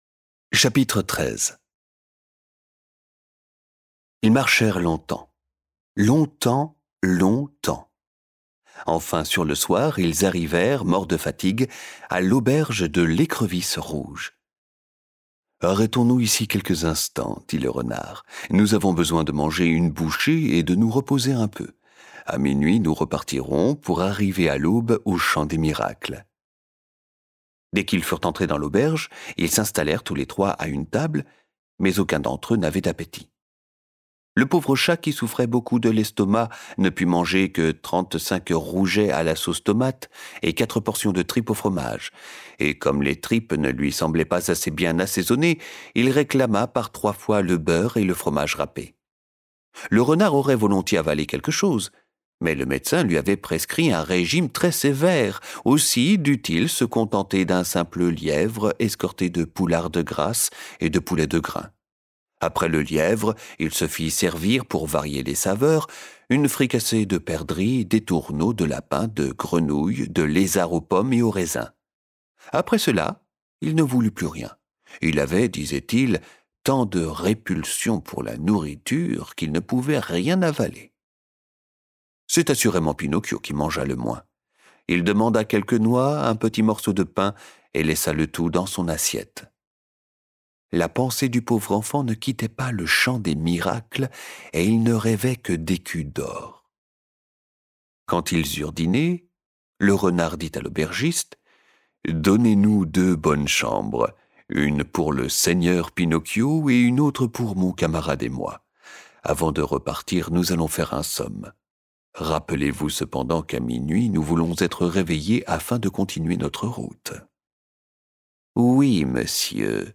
Envie de découvrir de la littérature audio en famille ?